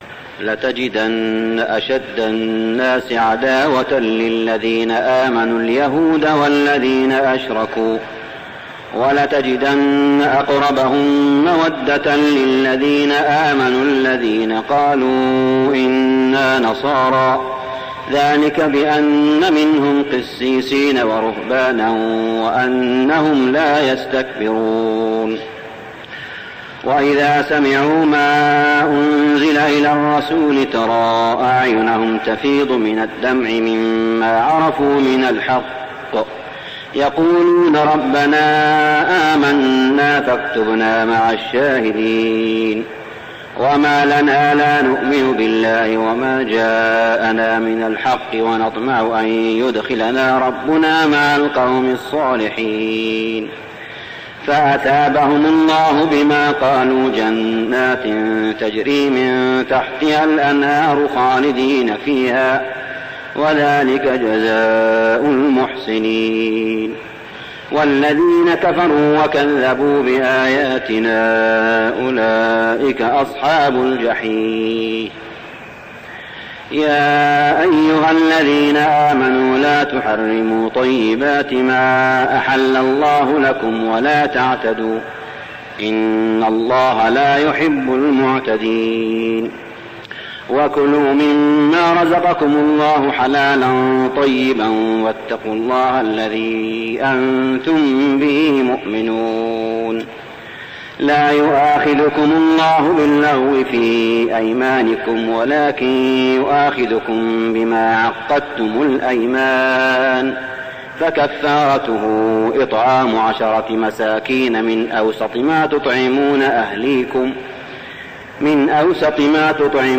صلاة التراويح ليلة 7-9-1409هـ سورتي المائدة 82-120 و الأنعام 1-35 | Tarawih prayer Surah Al-Ma'idah and Al-An'am > تراويح الحرم المكي عام 1409 🕋 > التراويح - تلاوات الحرمين